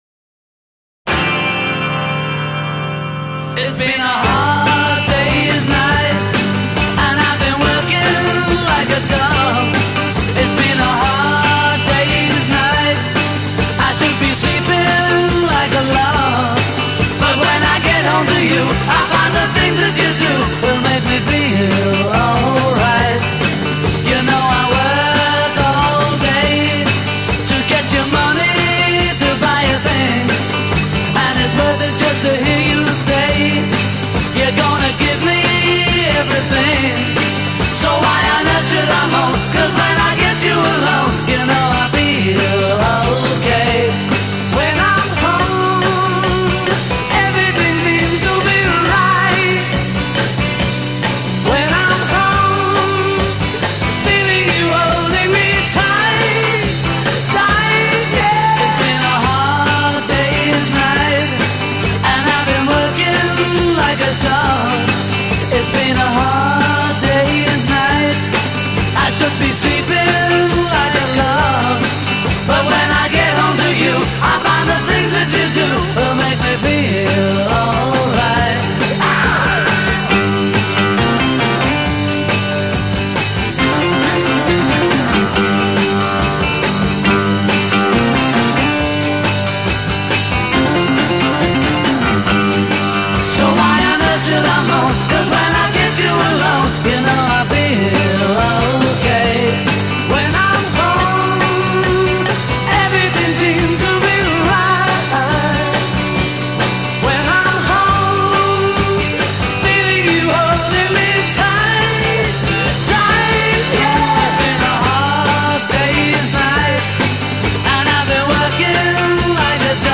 piano
bongos
Coda : 4+ repeat hook; arpeggiate chord (repeat and fade) a'